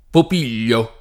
vai all'elenco alfabetico delle voci ingrandisci il carattere 100% rimpicciolisci il carattere stampa invia tramite posta elettronica codividi su Facebook Popiglio [ pop & l’l’o ] (antiq. Pupiglio [ pup & l’l’o ]) top. (Tosc.)